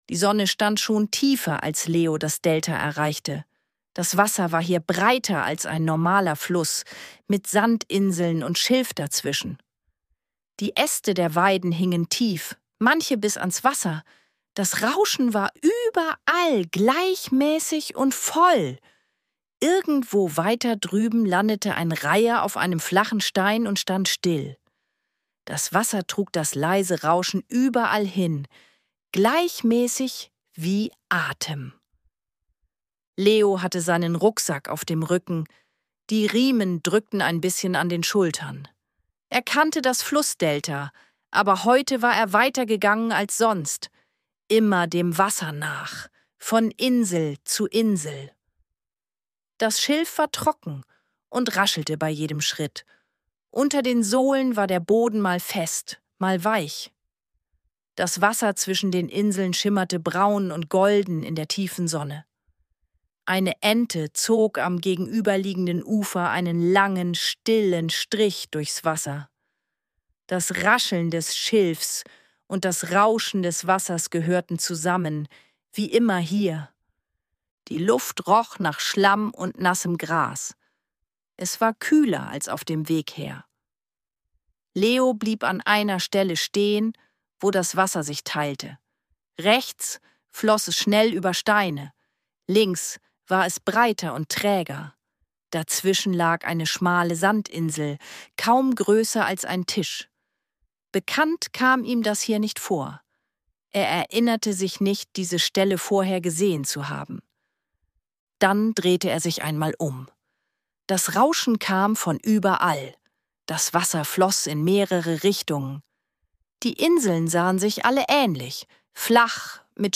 Eine ruhige Abenteuergeschichte für Kinder über Orientierung, Mut und Vertrauen in die eigenen Gedanken.
Ruhige Kindergeschichten zum Anhören